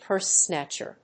アクセントpúrse‐snàtcher